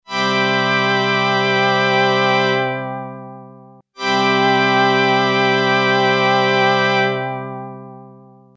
adsr EFFECT internal analog chorus based on a single Panasonic BBD IC MN3209 with no control but ON OFF- pseudo stereo gives a nice warmth to the sound.
XP: chorus off then on